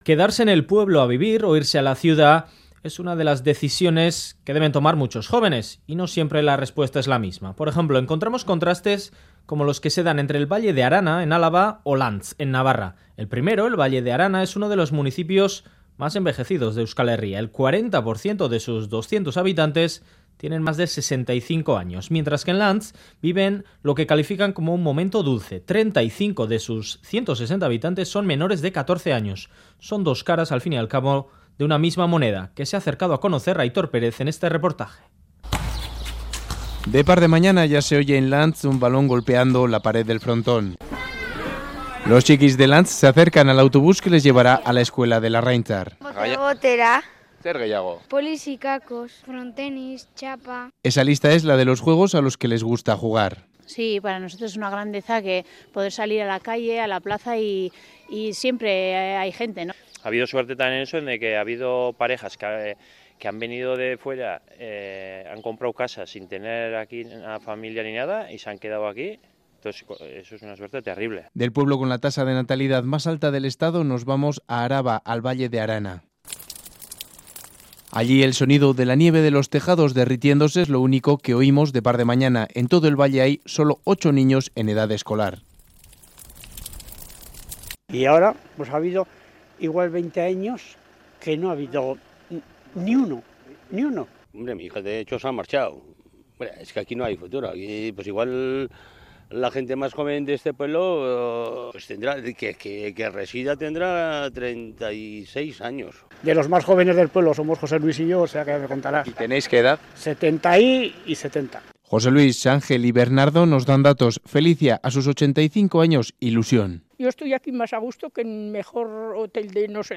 Radio Euskadi REPORTAJES Valle de Arana y Lantz, imagen de un contraste demográfico Última actualización: 05/03/2018 10:29 (UTC+1) El 40% de los 200 habitantes del municipio alavés tiene más de 65 años.